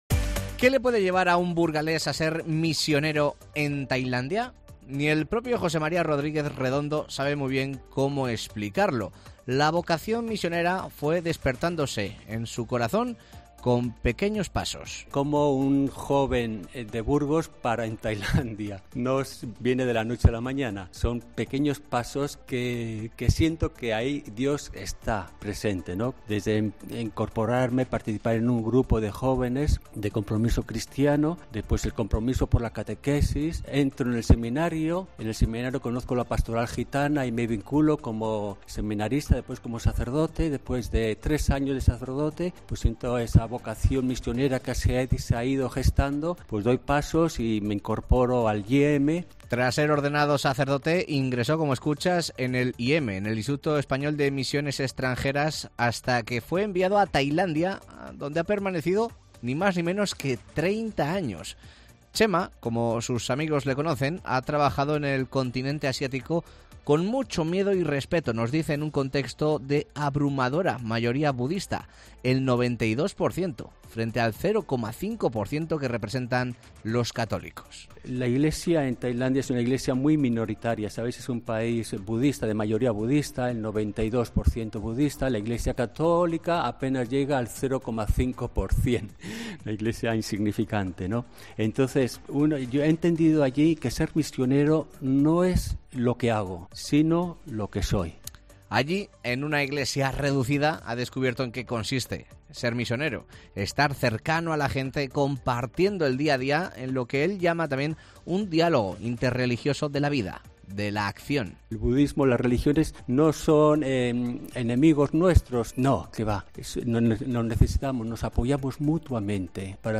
Dos misioneros relatan sus 30 años de experiencias en Benín y Tailandia, historias de vida marcadas por la entrega y la lucha por la dignidad humana